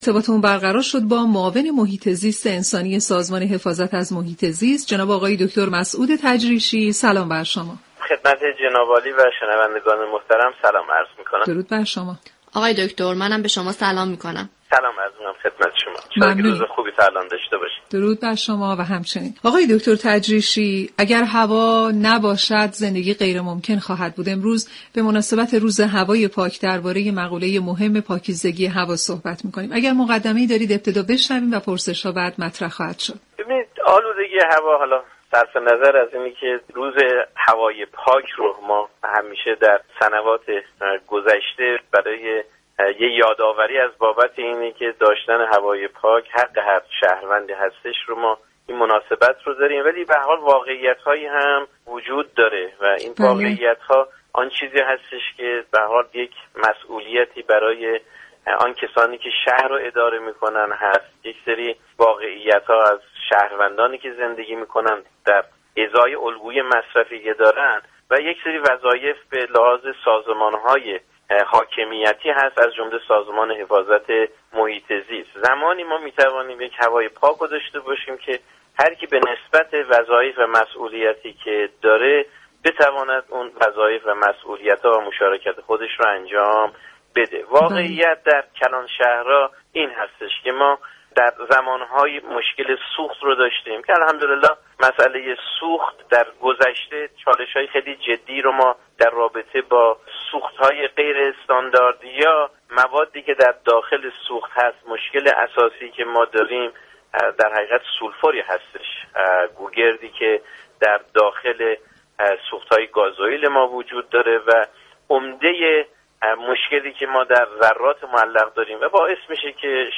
دكتر مسعود تجریشی معاون محیط زیست انسانی سازمان حفاظت محیط زیست در برنامه سیاره آبی رادیو ایران گفت : زمانی می توانیم هوای پاك داشته باشیم كه هر كس به نسبت وظایف خود، بتواند ایفای نقش كند.